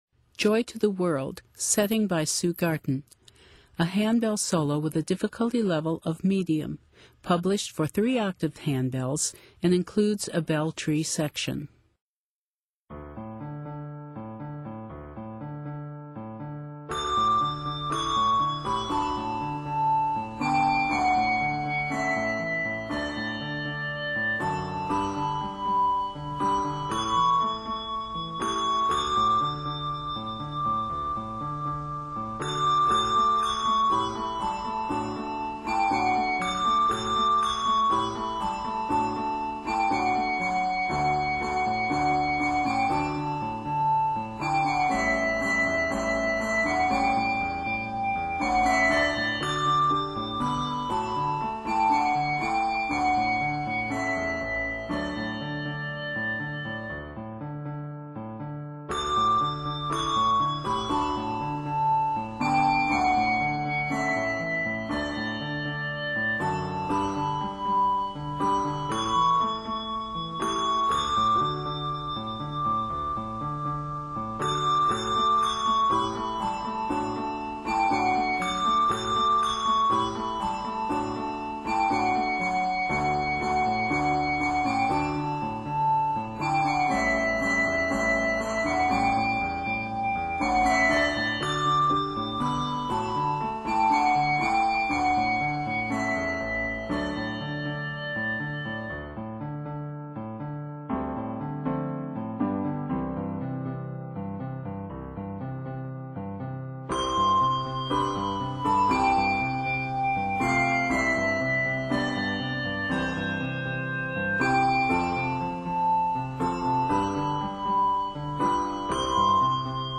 Instrument: Piano , Bell Tree